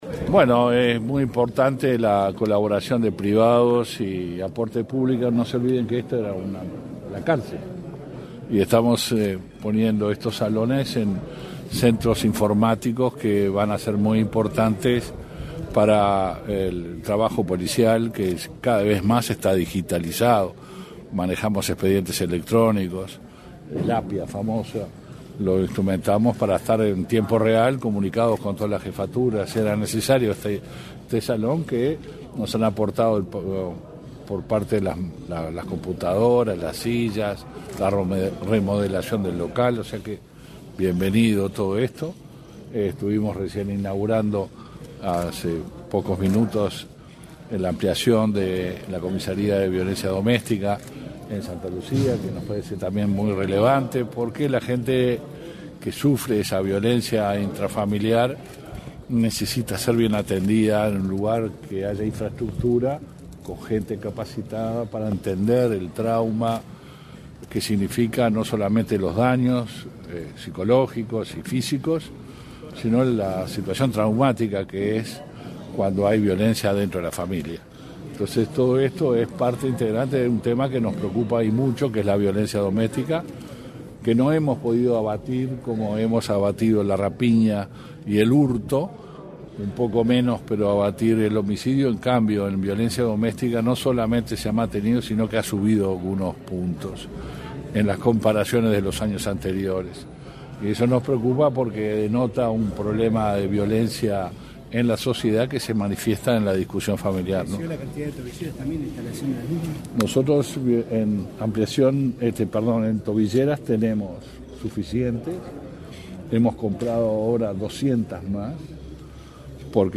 Declaraciones a la prensa del ministro del Interior, Luis Alberto Heber
Tras los actos, el ministro Luis Alberto Heber realizó declaraciones a la prensa.